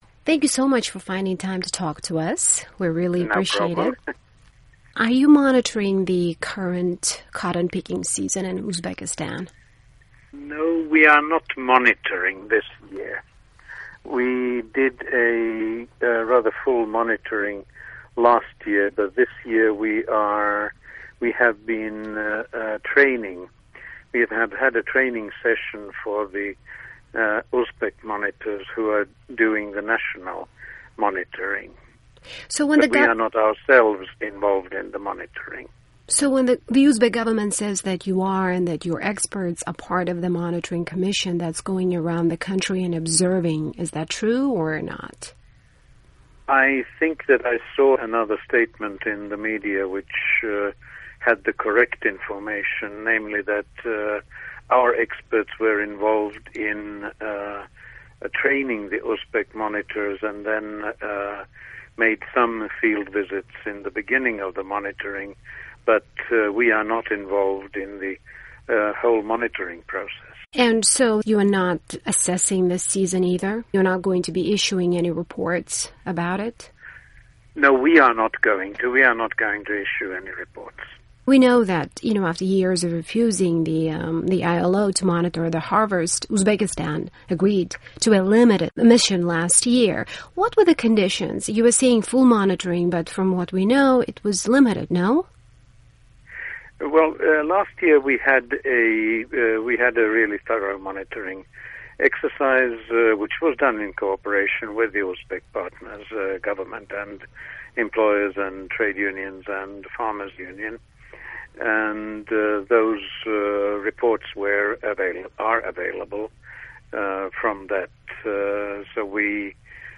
ILO, Uzbekistan and forced labor in its cotton sector - Interview